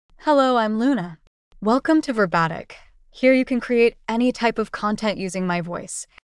Luna — Female English (United States) AI Voice | TTS, Voice Cloning & Video | Verbatik AI
Luna is a female AI voice for English (United States).
Voice sample
Listen to Luna's female English voice.
Female
Luna delivers clear pronunciation with authentic United States English intonation, making your content sound professionally produced.